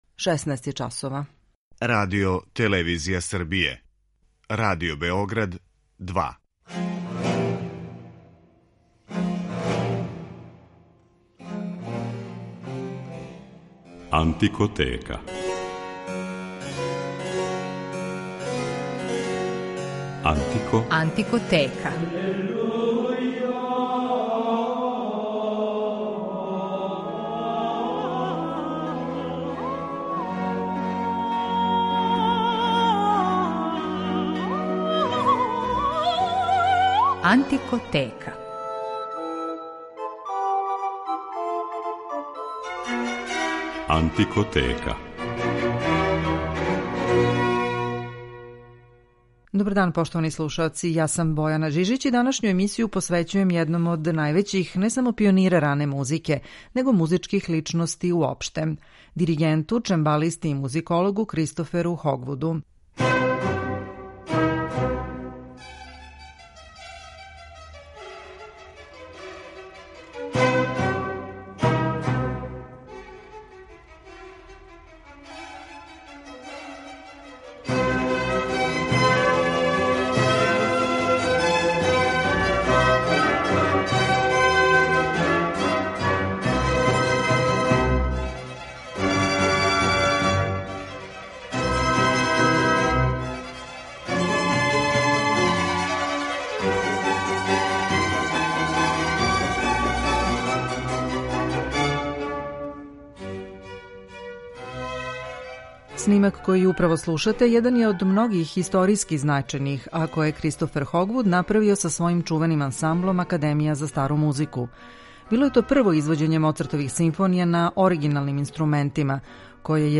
солисту на чембалу и клавикорду
ораторијума